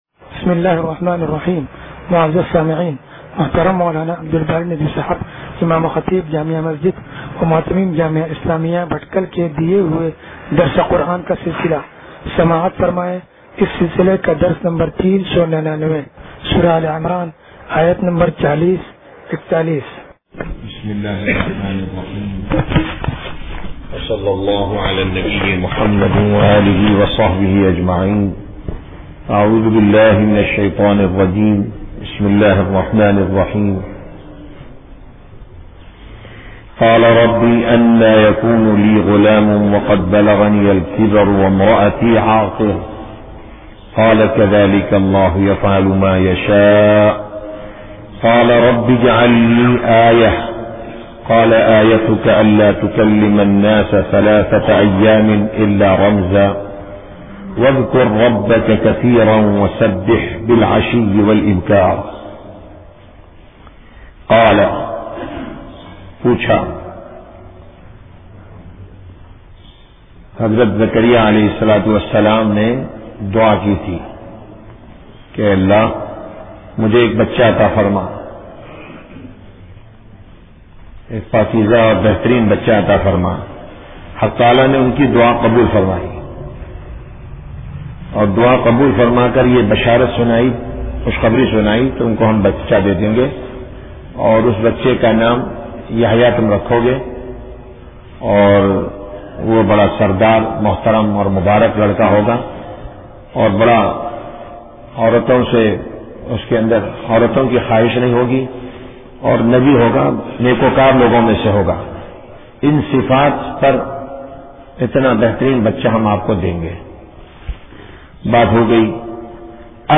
درس قرآن نمبر 0399